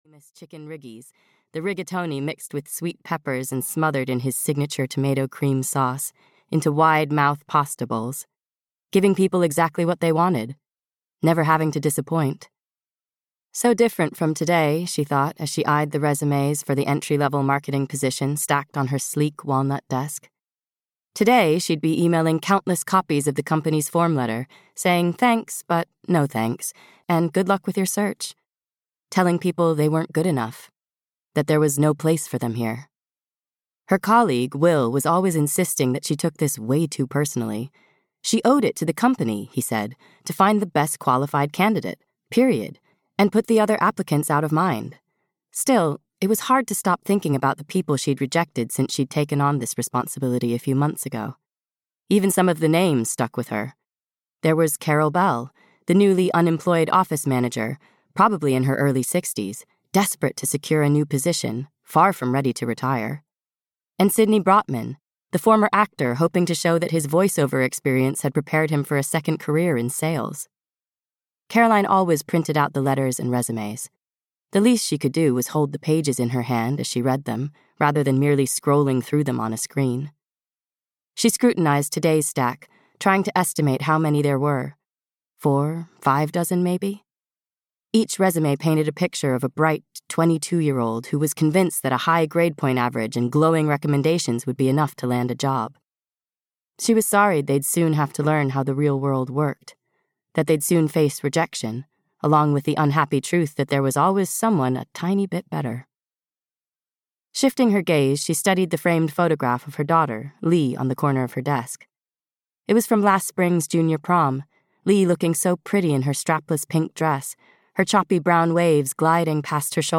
The Lily Garden (EN) audiokniha
Ukázka z knihy